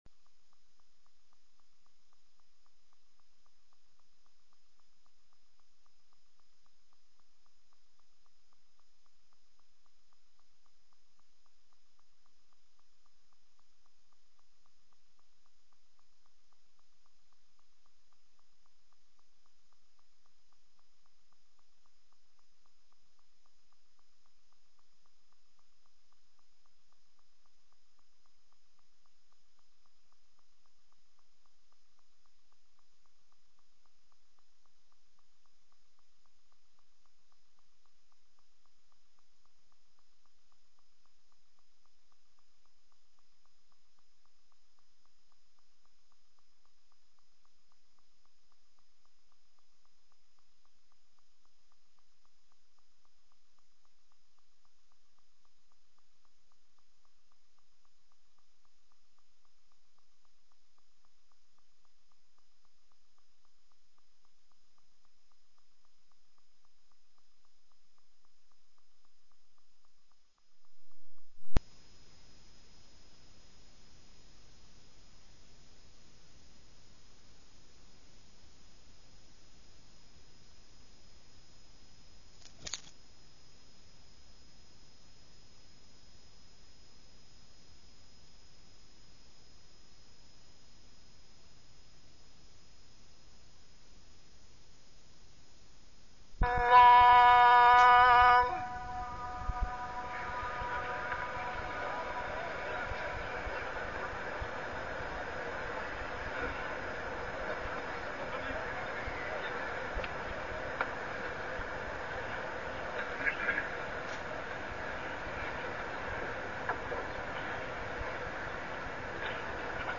تاريخ النشر ٥ شوال ١٤١٩ هـ المكان: المسجد الحرام الشيخ: عمر السبيل عمر السبيل إتباع الهوى والشهوات